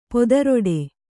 ♪ podaroḍe